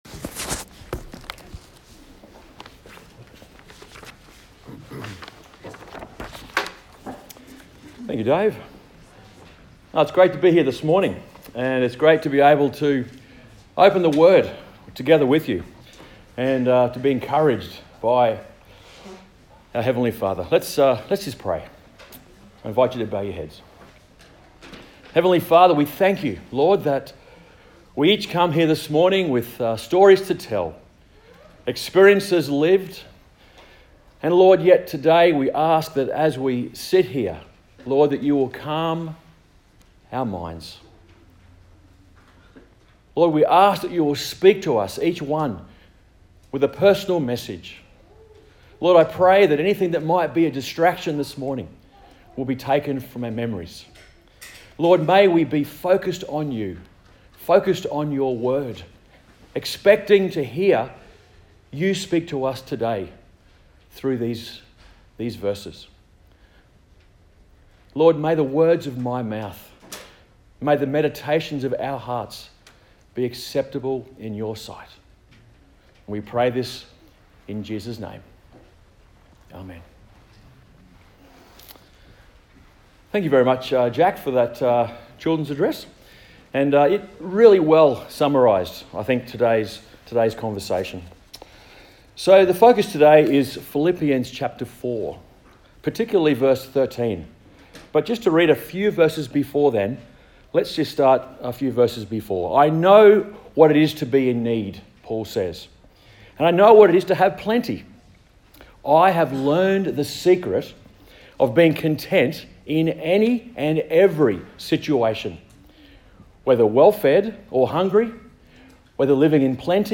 Philippians Passage: Philippians 4:4-13 Service Type: Sunday Morning